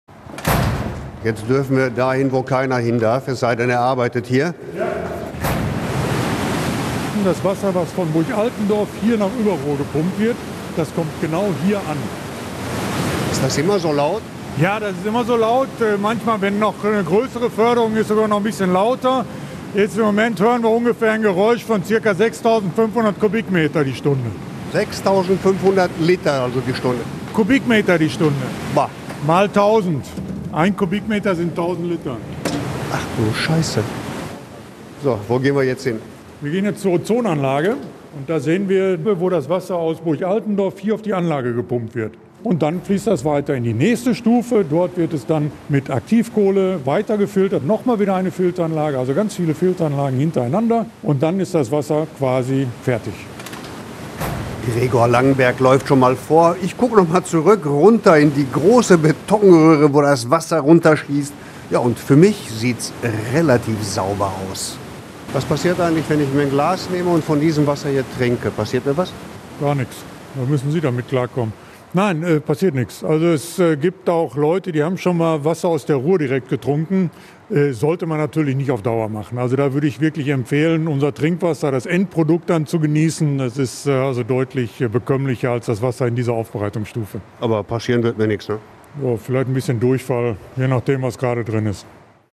rundgang-wasserwerk.mp3